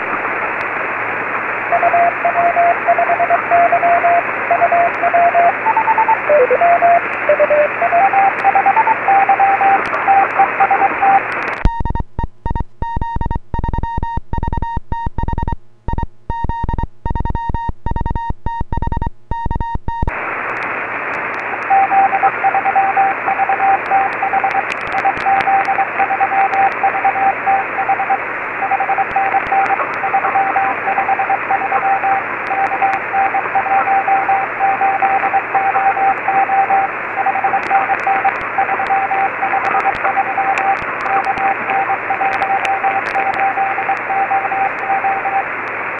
IARU VHF 09/2014 CATEGORIA 6 ORE